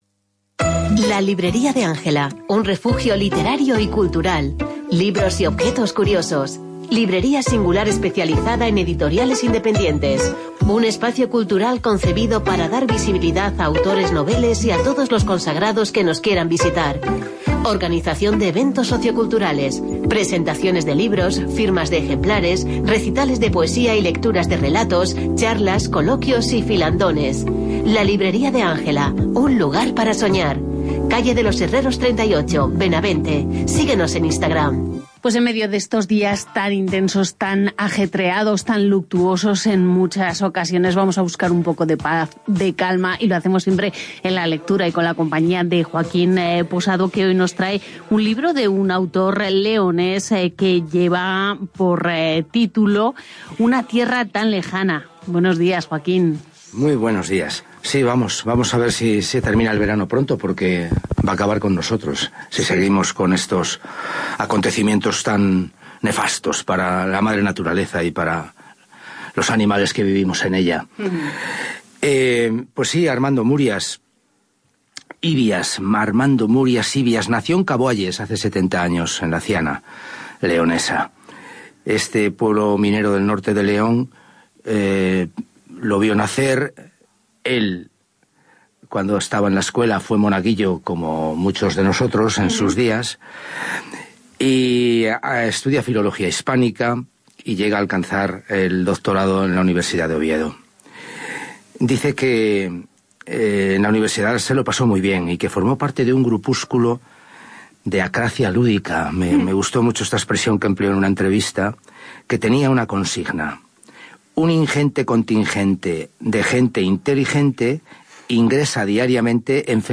en el programa de Hoy por Hoy Zamora (Cadena SER)